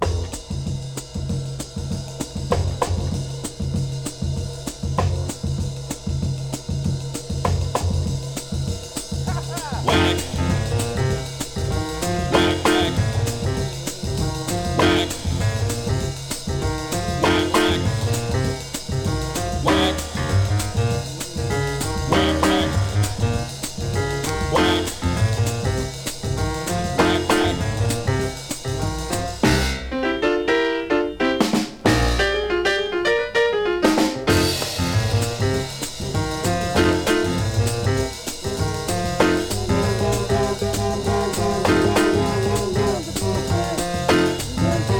モッドなテイスト大盛りでこれまたある種の青春の香りが吹き上げます。
Soul, Funk　UK　12inchレコード　33rpm　Mono/Stereo